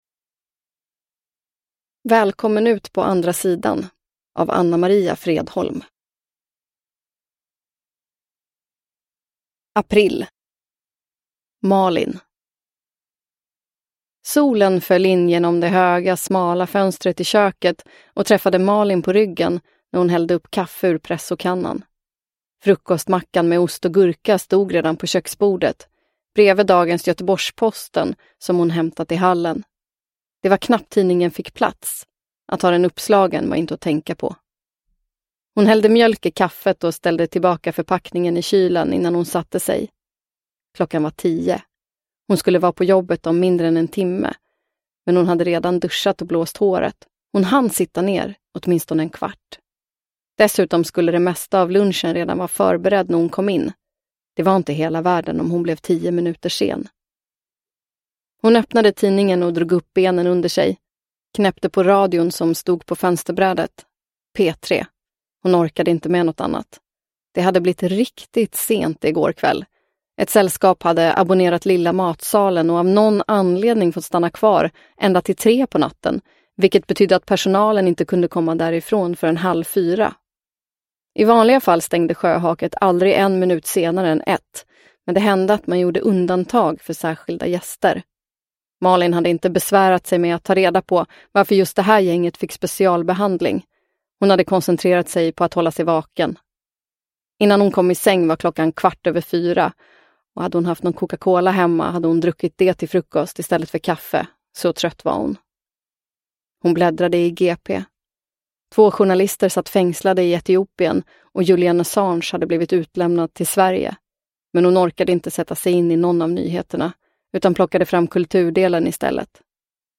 Välkommen ut på andra sidan – Ljudbok – Laddas ner